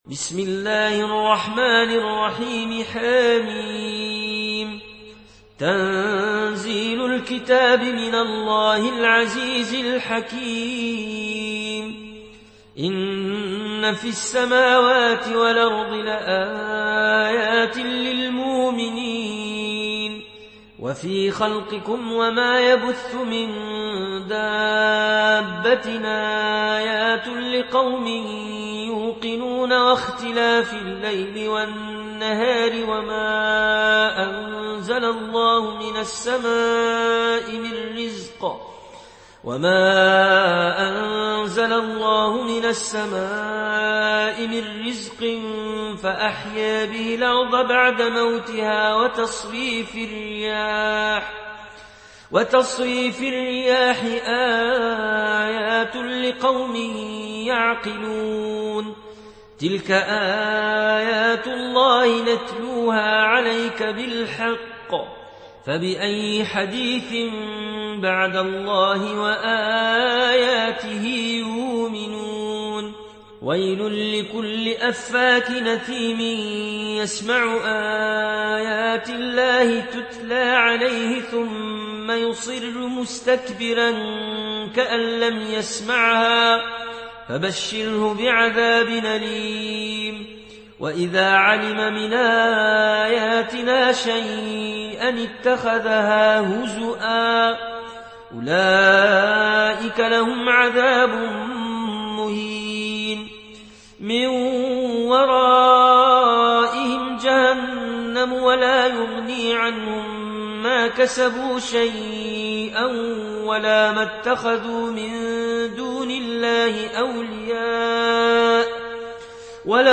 برواية ورش عن نافع